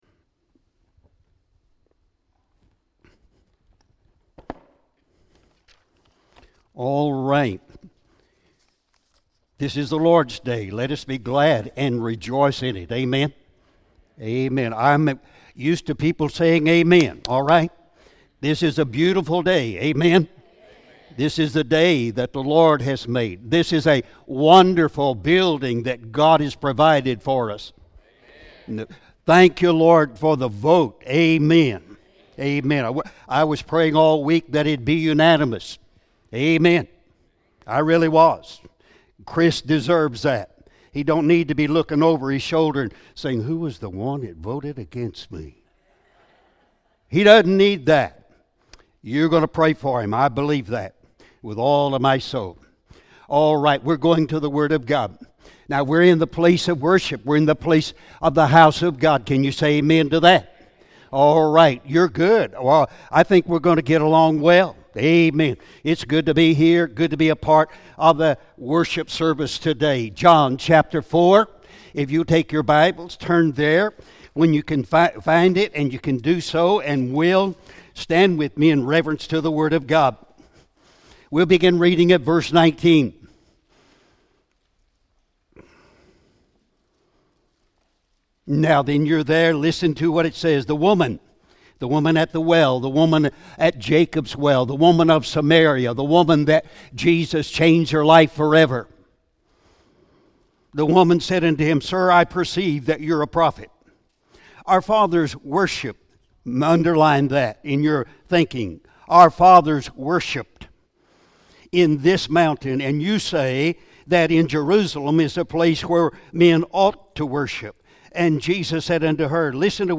Sunday Sermon May 6, 2018
Sermons